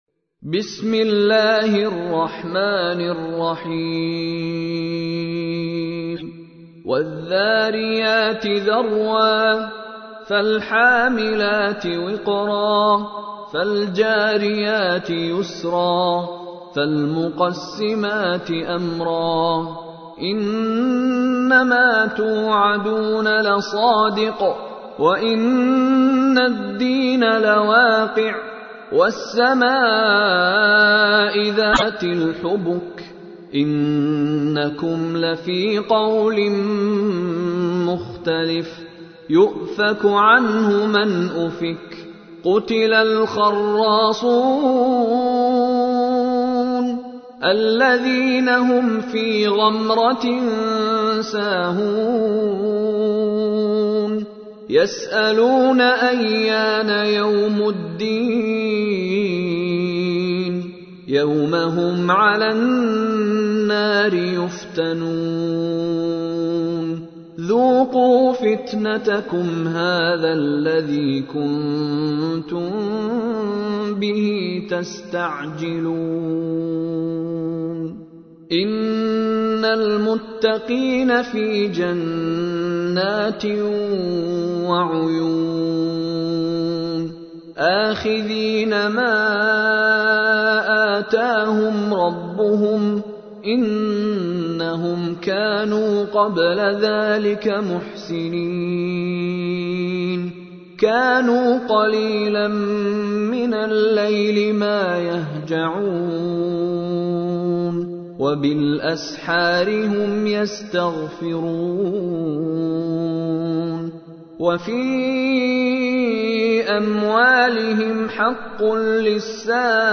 تحميل : 51. سورة الذاريات / القارئ مشاري راشد العفاسي / القرآن الكريم / موقع يا حسين